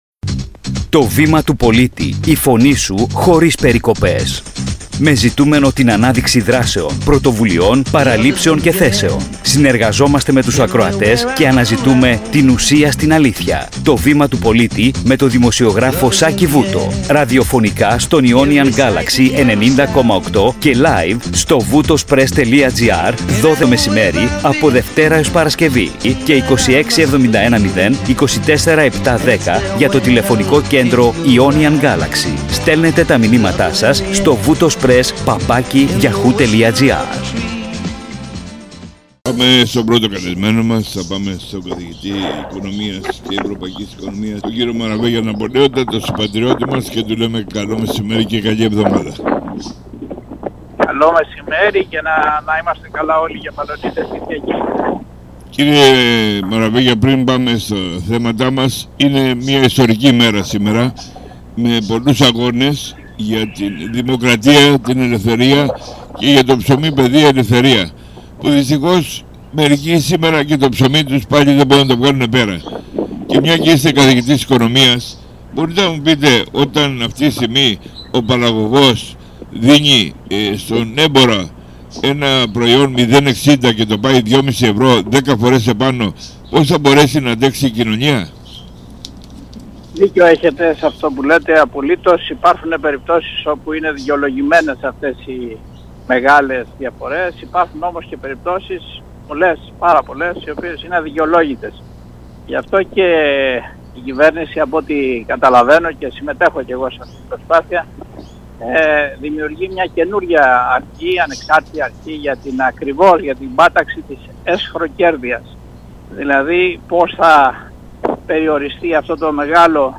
ΚΥΡΙΑ ΣΗΜΕΙΑ ΣΥΝΕΝΤΕΥΞΗΣ ΝΑΠΟΛΕΩΝΑ ΜΑΡΑΒΕΓΙΑ